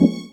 ORGAN-32.wav